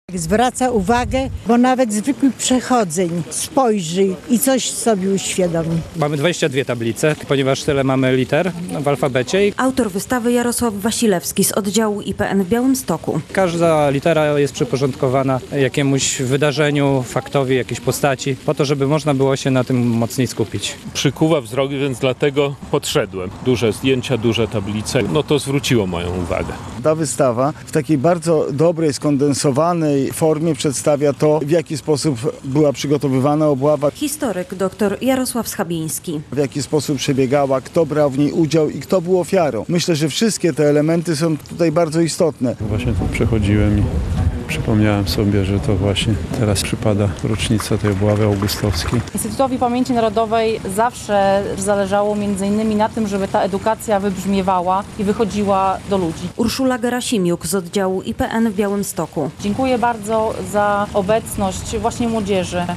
Wernisaż edukacyjnej wystawy plenerowej "...I nigdy nie wrócili do domu" w Suwałkach, 10.06.2025, fot.
Edukacyjna wystawa plenerowa "...I nigdy nie wrócili do domu" - relacja